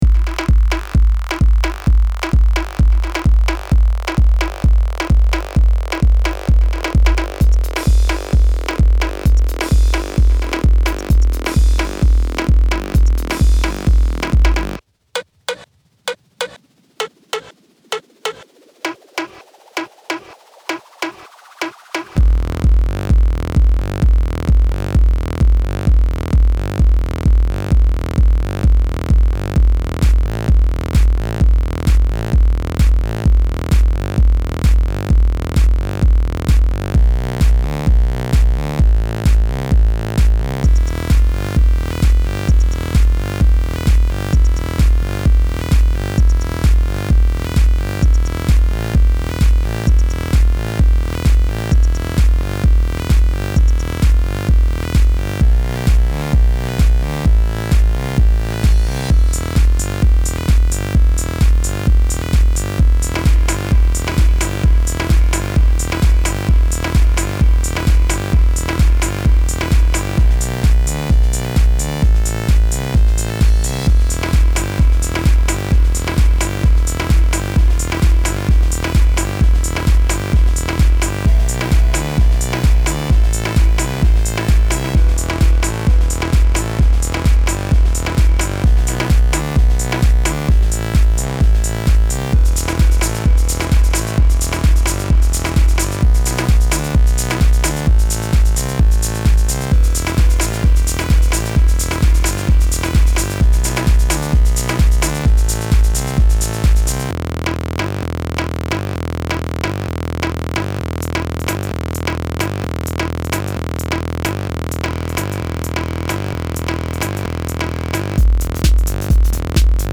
Minimal Techno Song